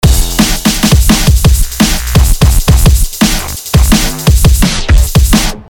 肮脏的神经朋克鼓和贝斯
Tag: 170 bpm Drum And Bass Loops Drum Loops 973.47 KB wav Key : Unknown